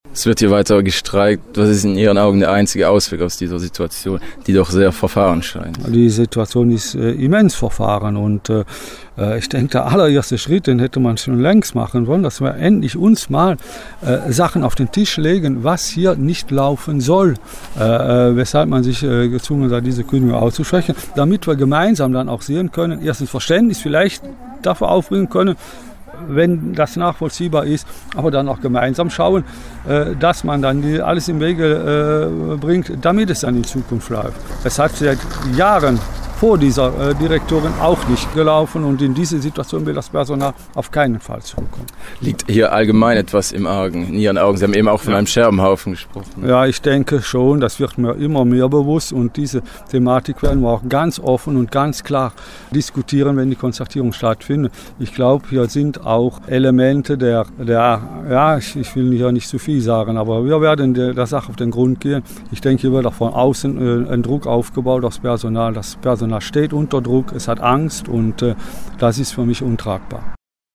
GrenzEcho-Interview